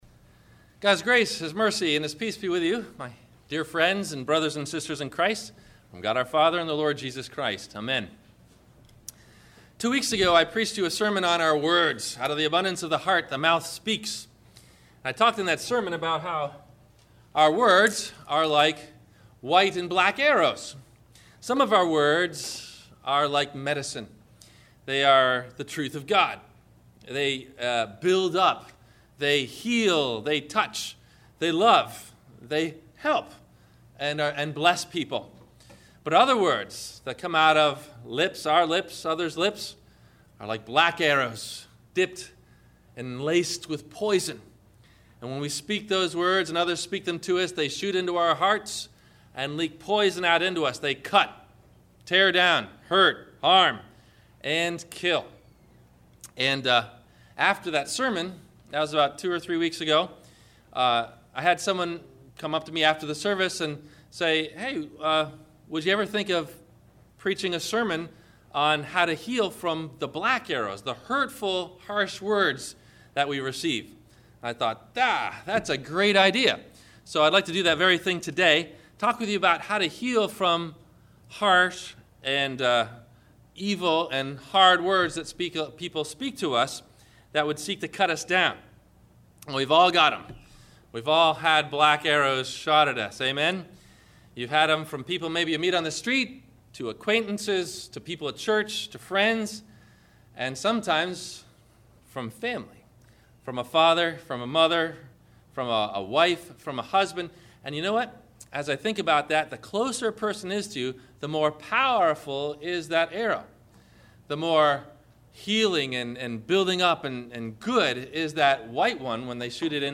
How can we learn to heal from black arrows? Listen for these questions and answers about Healing From Black Arrows below in the 2-part Video or 1-part MP3 Audio Sermon below.